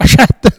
Cri pour chasser le chat ( prononcer le crti )